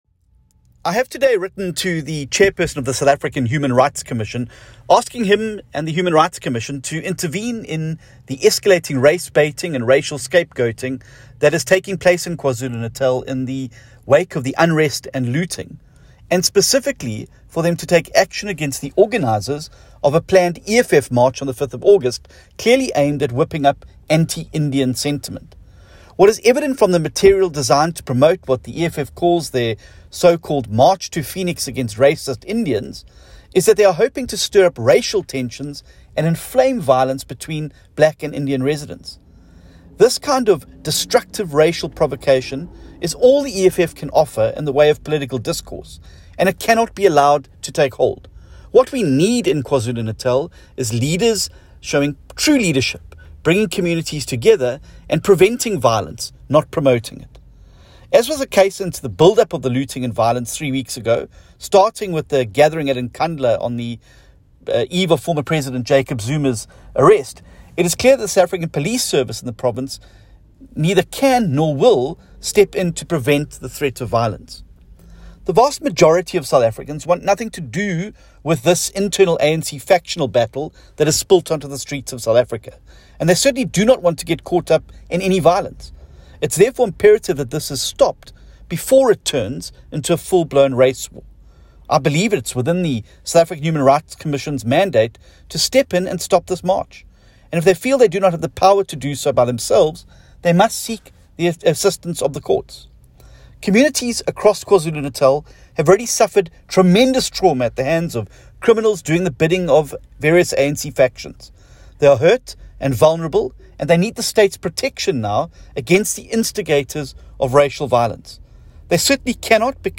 find attached a soundbite by John Steenhuisen MP.